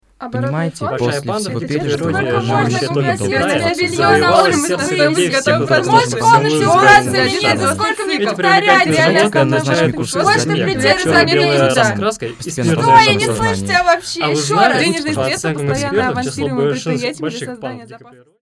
Звуки болтовни
Шум людской болтовни